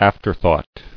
[af·ter·thought]